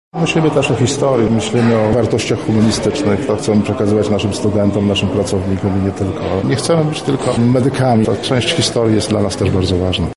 W auli Auditorium Maximum Uniwersytetu Medycznego w Lublinie odbyła się konferencja poświęcona 450. rocznicy zawarcia Unii Lubelskiej.